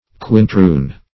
Search Result for " quintroon" : The Collaborative International Dictionary of English v.0.48: Quintroon \Quin*troon"\, n. [Sp. quinteron the off-spring of a quadroon and a white.]